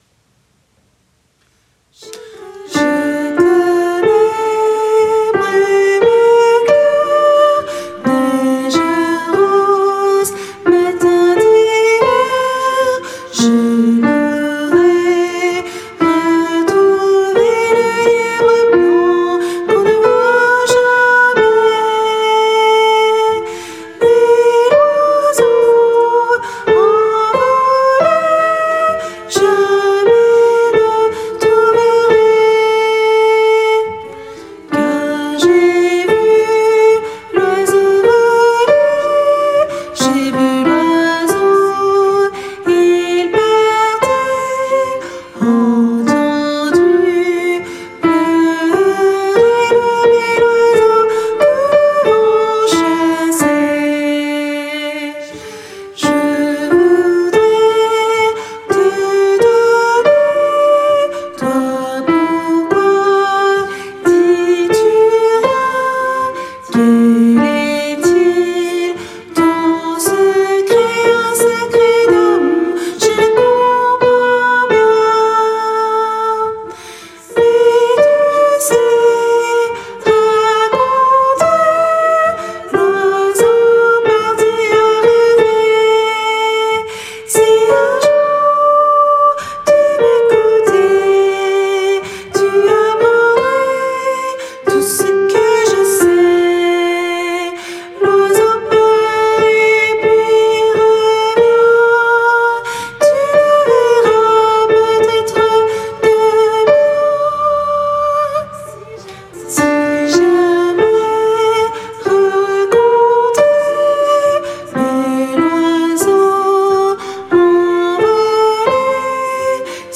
Tenor et autres voix en arrière-plan